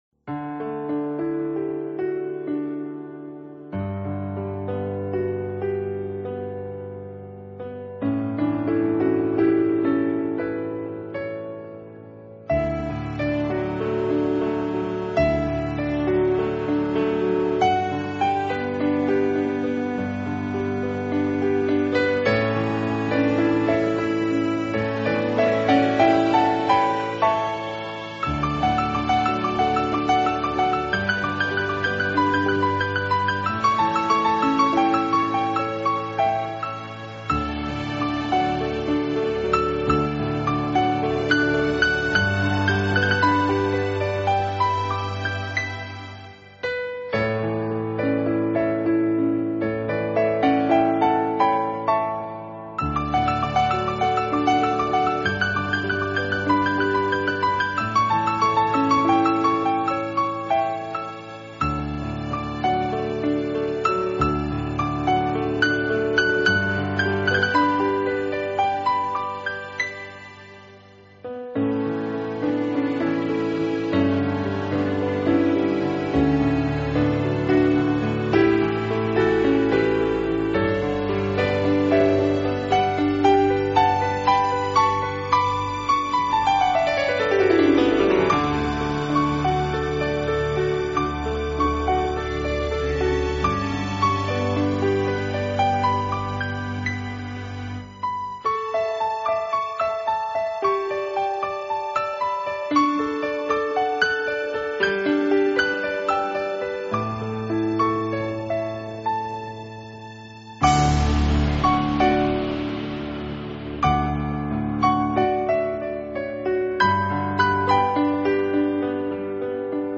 音乐类型:  Rock/Pop, Jazz, New  Age, Piano
清澈透明宁静冰凉，又象天使般可爱灵动，很女性的音乐很女性的心境。